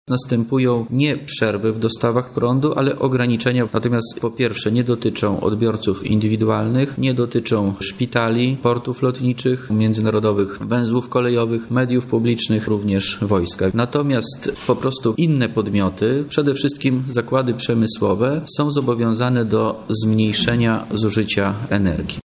– Na chwilę obecną nie ma zagrożenia aby odbiorcy indywidualni podlegali ograniczeniom w dostawach energii – mówi wojewoda lubelski Wojciech Wilk